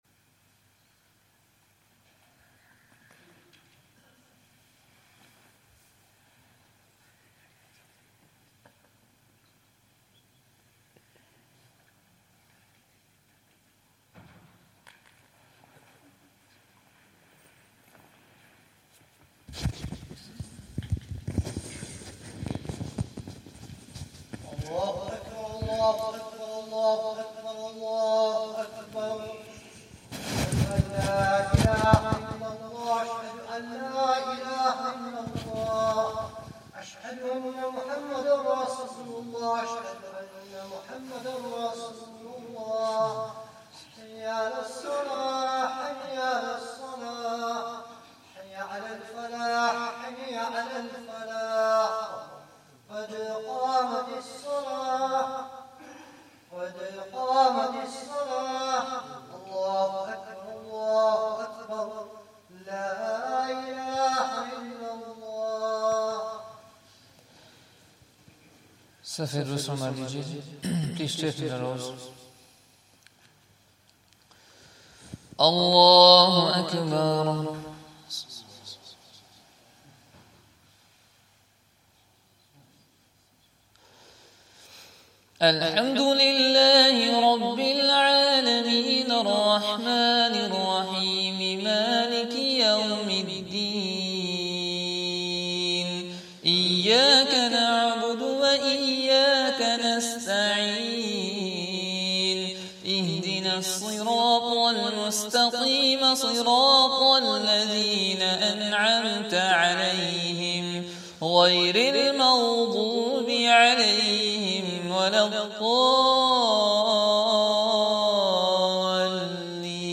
Isha Salah
Zakariyya Jaam'e Masjid, Bolton